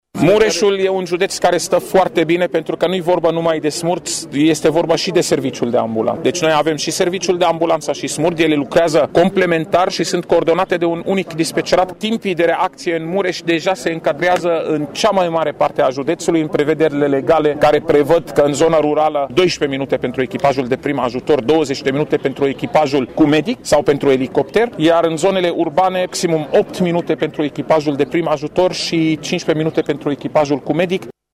Prezent azi la inaugurarea punctului de lucru de la Acăţari, fondatorul SMURD  a declarat că în acest an se vor mai deschide 10-15 astfel de puncte de lucru.